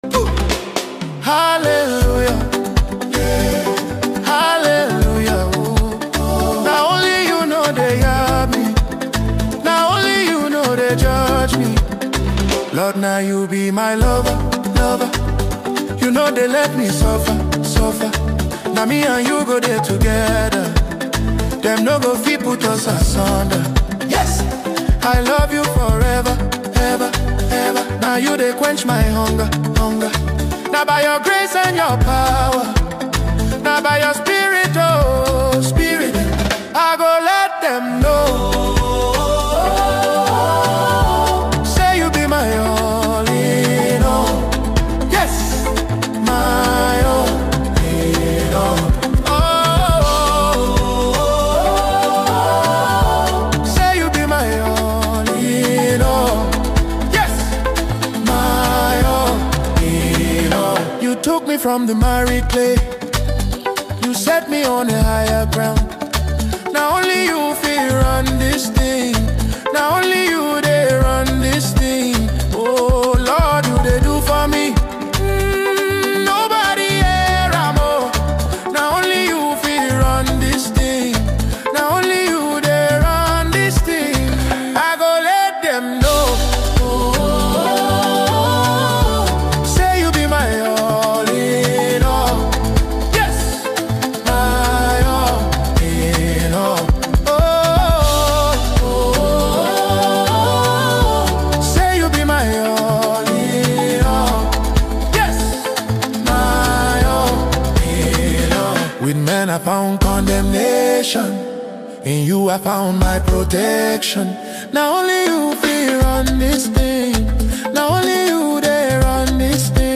There is a calm pull in this track that is hard to ignore.